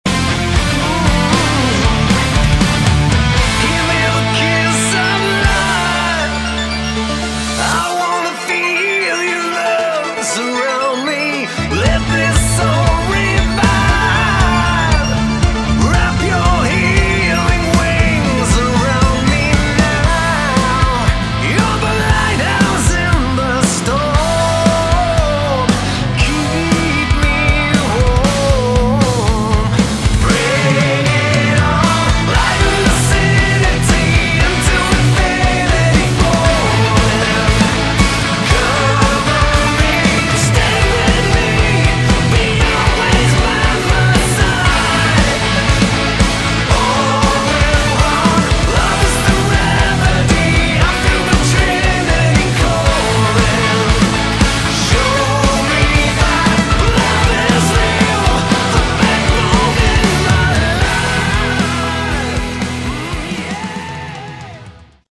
Category: Hard Rock / Melodic Metal
guitars, keyboards
bass
drums